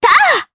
Laudia-Vox_Attack3_jp_b.wav